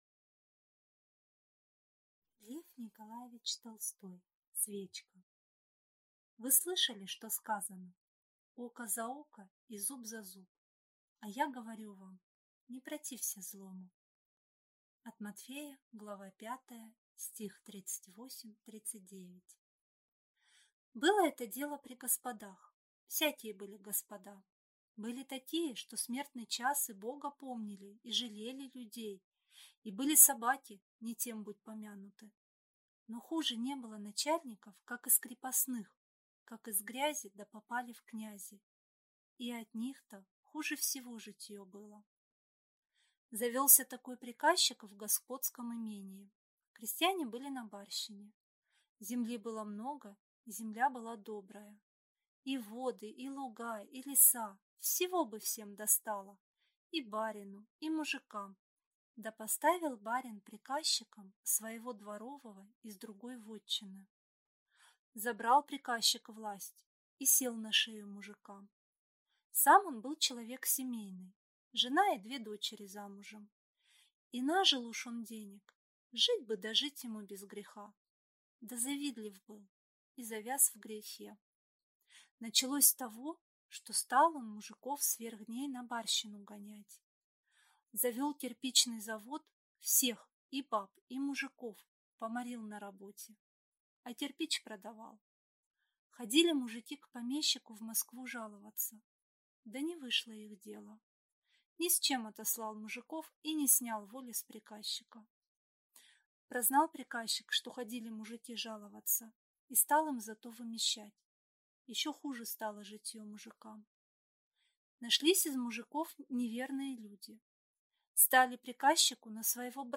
Аудиокнига Свечка